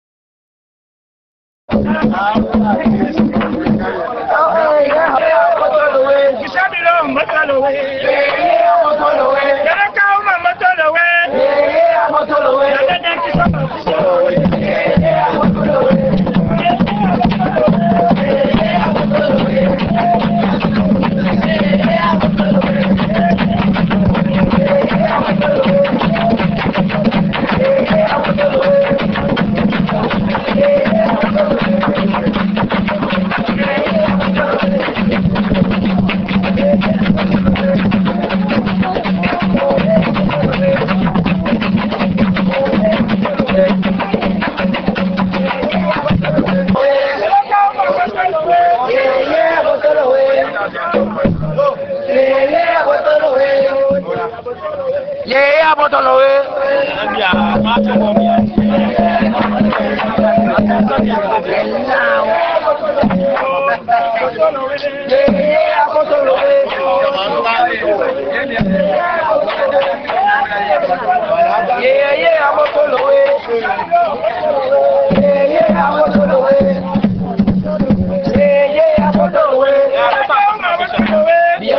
enregistrement durant une levée de deuil (Puubaaka)
danse : songe (aluku) ; levée de deuil ;
Genre songe
Pièce musicale inédite